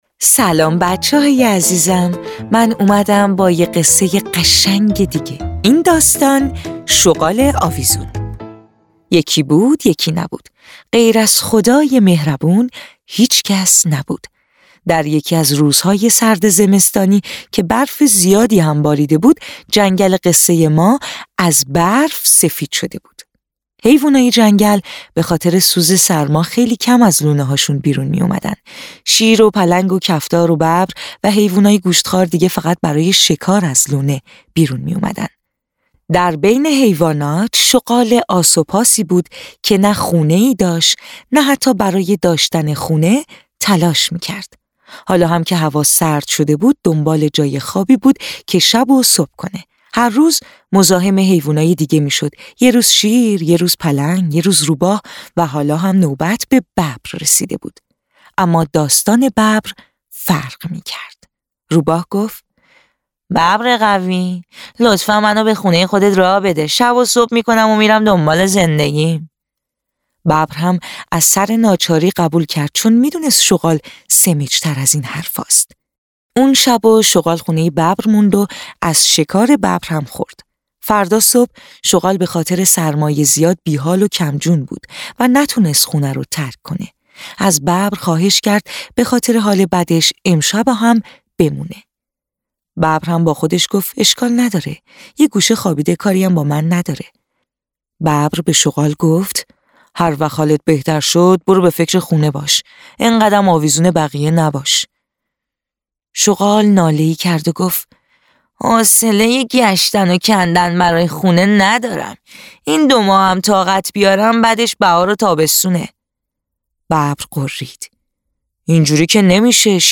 قصه‌های کودکانه صوتی – این داستان: شغال آویزون
تهیه شده در استودیو نت به نت